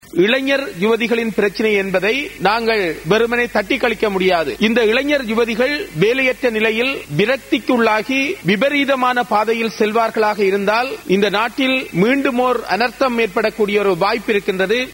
இந்த பிரேரணை தொடர்பில் உரையாற்றிய நாடாளுமன்ற உறுப்பினர் சிறிநேசன், இளைஞர் யுவதிகளின் பிரச்சினையை வெறுமனே தட்டி கழித்துவிட முடியாது என குறிப்பிட்டார்.
குரல் சிறிநேசன்